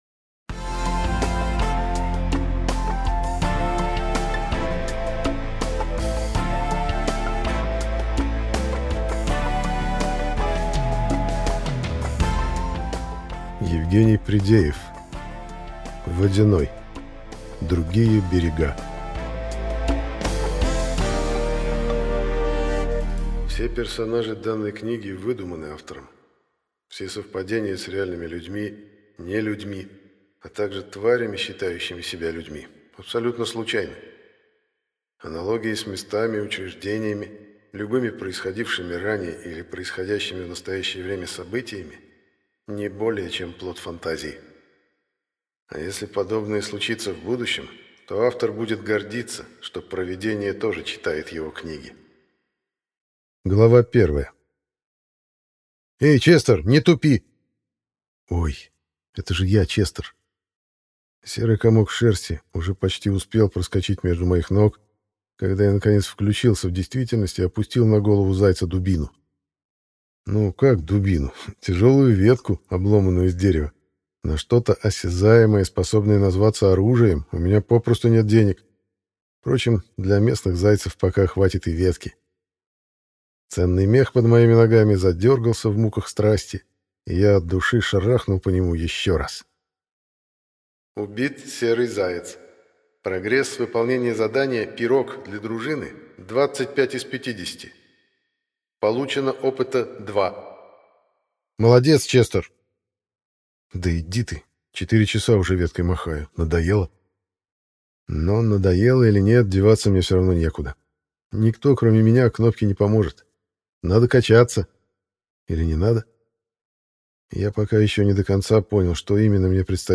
Аудиокнига Водяной. Другие берега | Библиотека аудиокниг